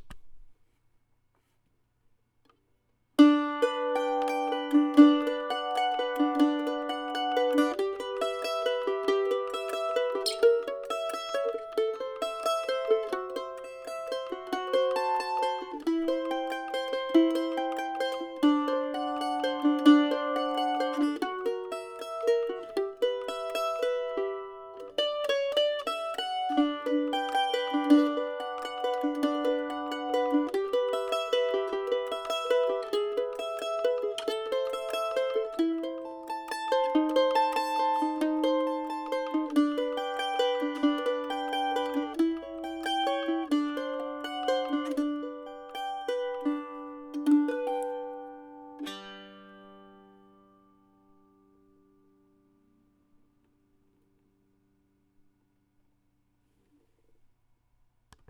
Gibson Lloyd Loar F5 Mandolin - 1924 - Sound Clips - New Acoustic Gallery - Finest Instruments
Crosspicking
Study for 2 Mandolins